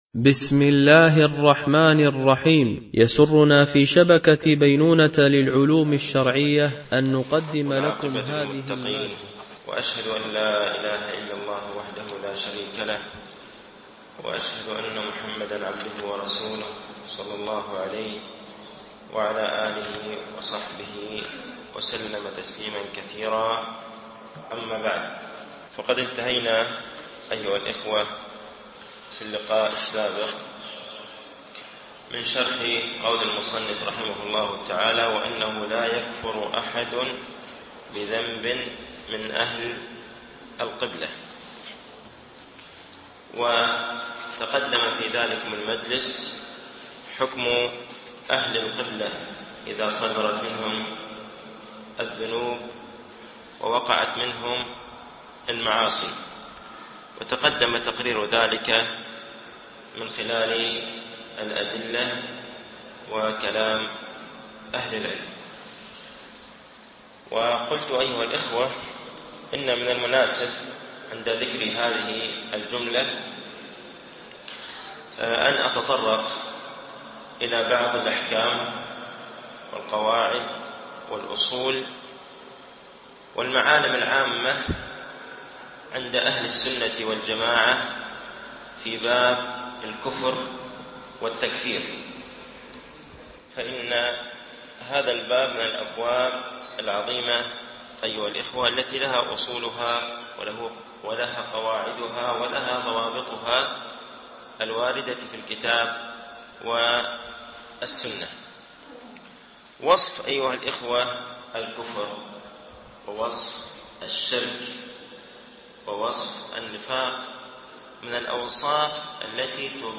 شرح مقدمة ابن أبي زيد القيرواني ـ الدرس السادس و الستون